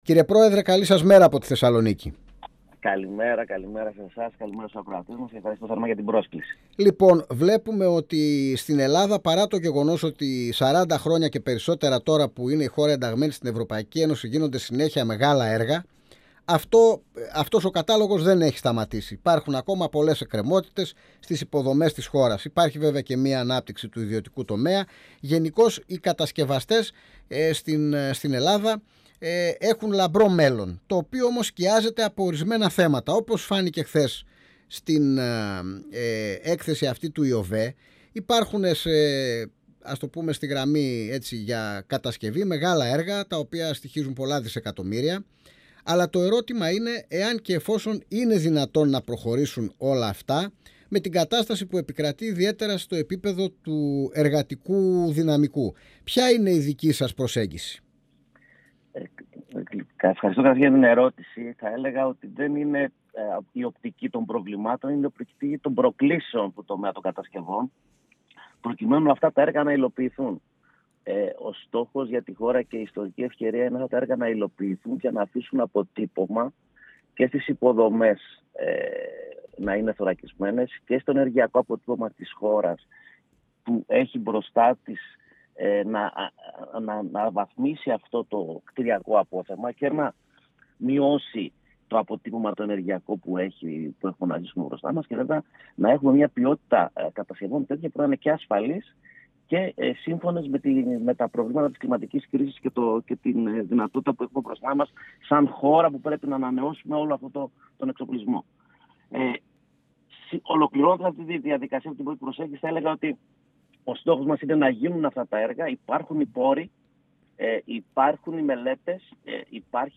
Οι ευρωπαϊκοί πόροι πρέπει να αξιοποιηθούν, να δώσουμε προτεραιότητα στα έργα του Ταμείου Ανάκαμψης και να φτιάξουμε έναν βιώσιμο κατασκευαστικό κλάδο». 102FM Αιθουσα Συνταξης Συνεντεύξεις ΕΡΤ3